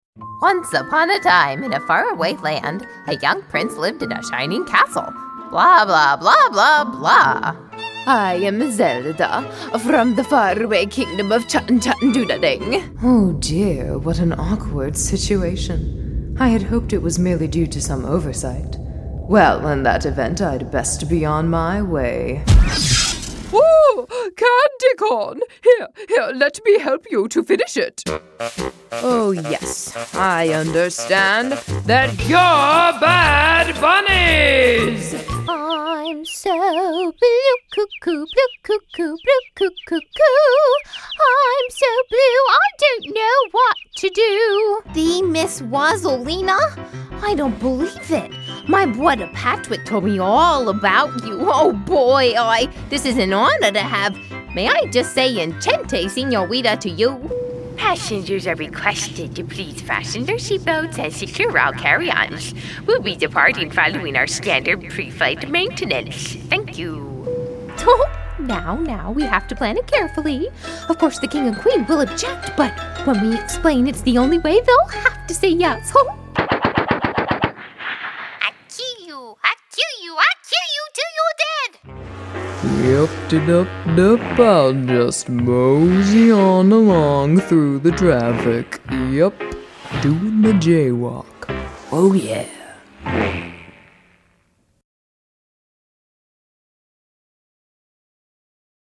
Female Voice Over, Dan Wachs Talent Agency.
Warm, Friendly, Conversational
Animation